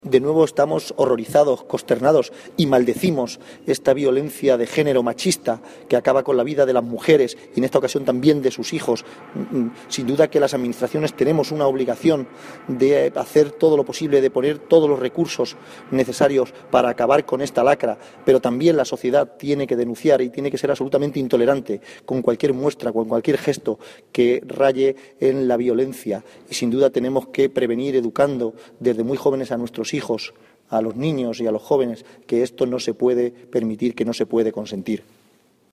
Declaraciones Presidente Diputación de Ciudad Real